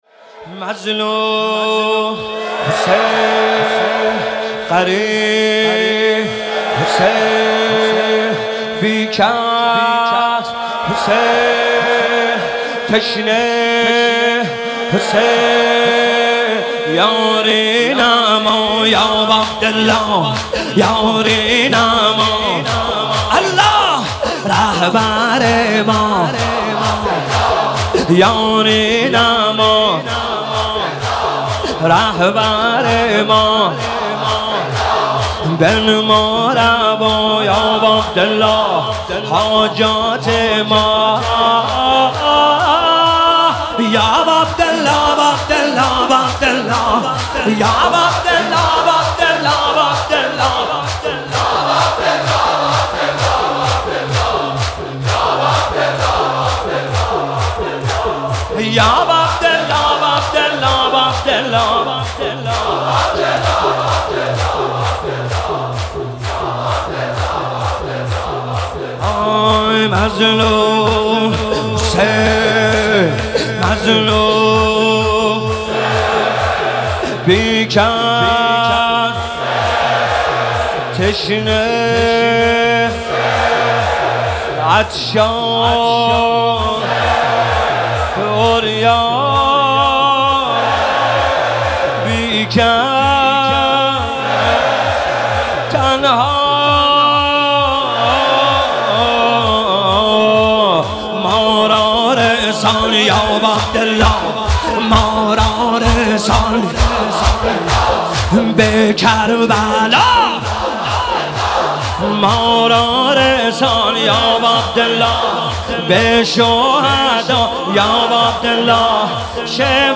نوحه
مکتب الزهرا